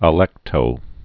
(ə-lĕktō)